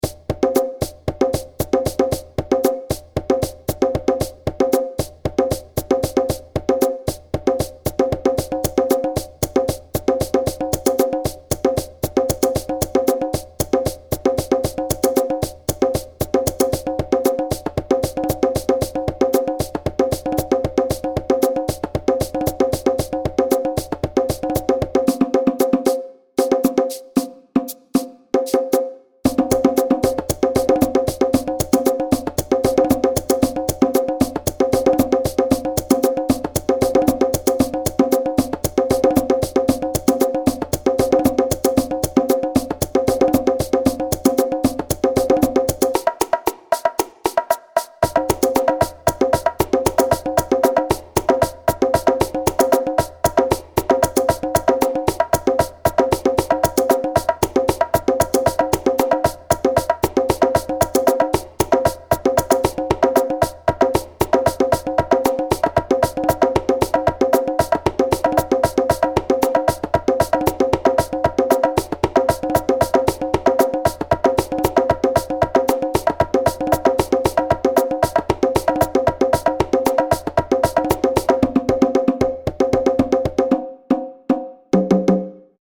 Secuencia desarrollada de percusión (bucle)
Música electrónica
percusión
melodía
repetitivo
rítmico
sintetizador